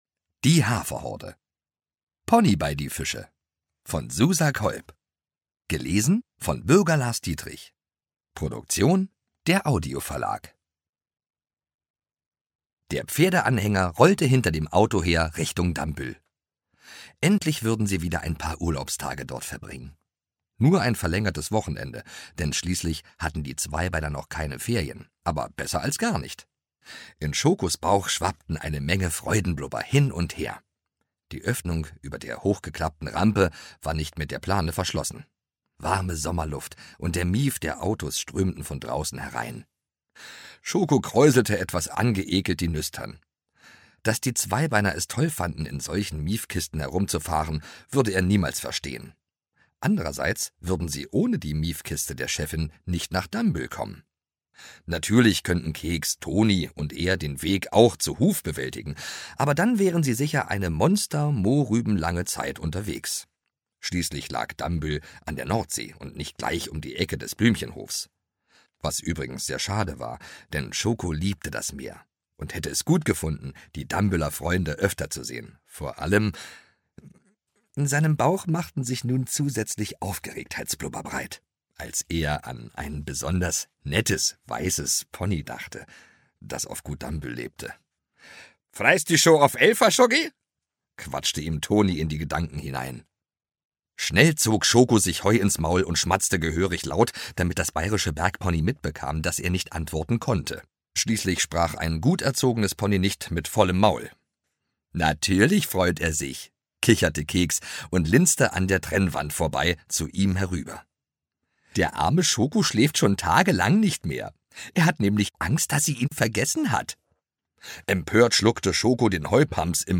Die Haferhorde – Teil 18: Pony bei die Fische Ungekürzte Lesung mit Bürger Lars Dietrich
Bürger Lars Dietrich (Sprecher)